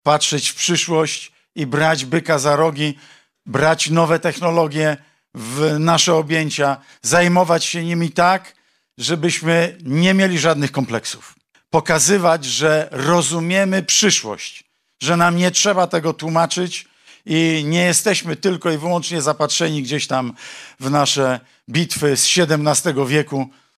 O dwóch skrzydłach europejskiej prawicy – tożsamościowym i rozwojowym – mówił w Lublinie polityk PIS, Mateusz Morawiecki. Były premier bierze udział w Kongresie Młodych w Lublinie.